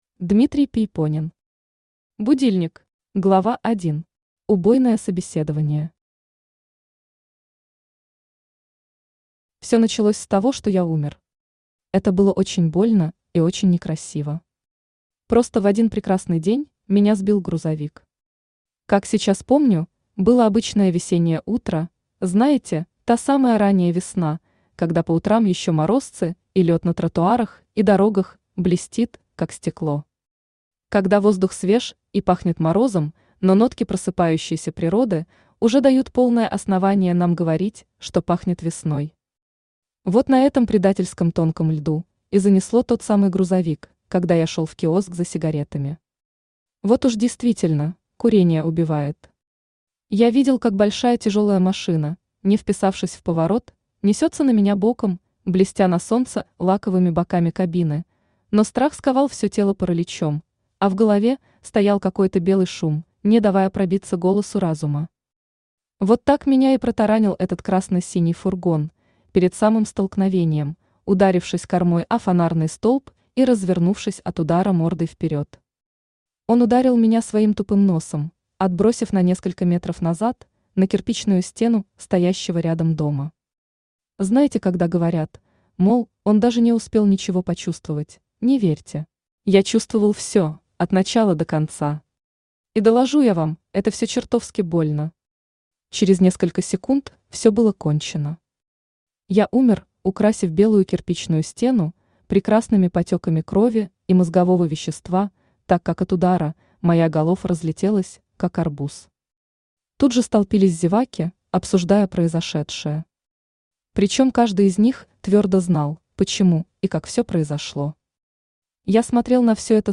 Аудиокнига Будильник